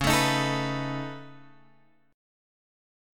C# 7th Suspended 2nd Sharp 5th